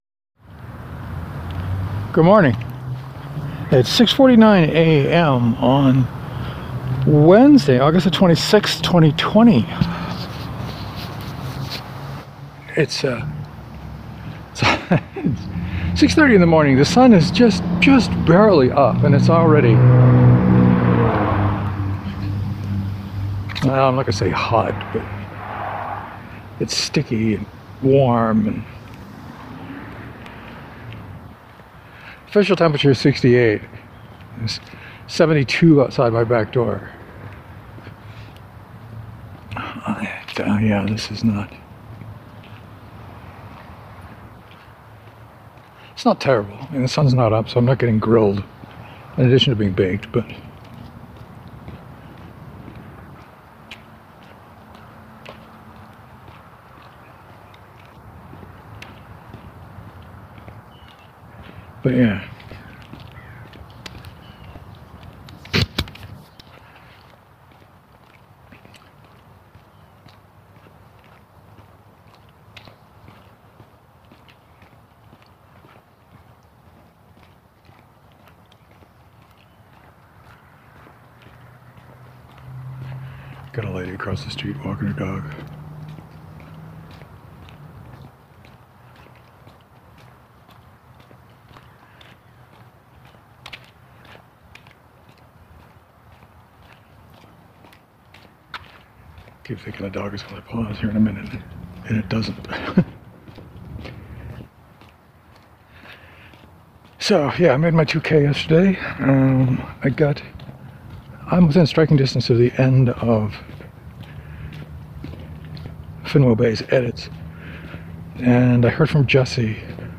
Ever thought about wearing a GoPro during your walk? the Daily Picture seems to give a rural flavor to your walk that the street noises/encounters tend to contradict.